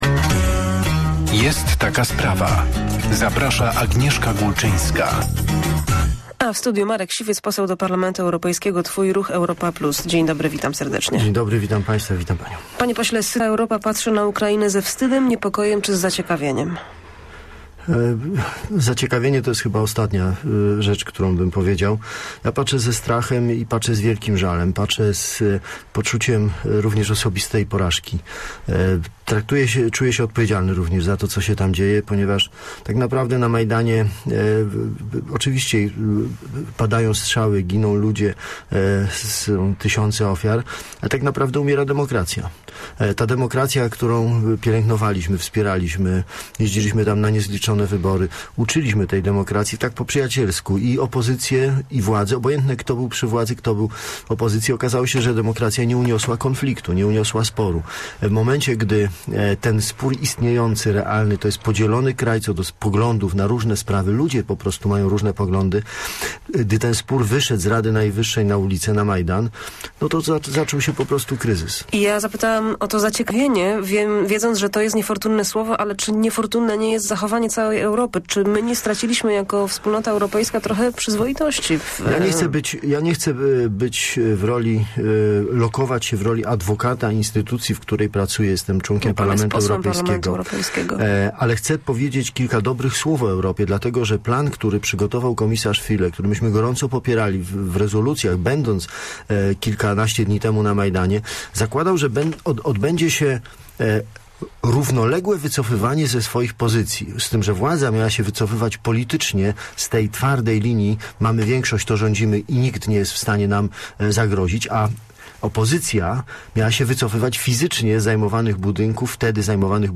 - To, co dzieje się na kijowskim Majdanie, to także moja osobista porażka - powiedział w Radiu Merkury wielkopolski poseł do Parlamentu Europejskiego Marek Siwiec. Wydarzenia ostatnich godzin nazywa śmiercią demokracji.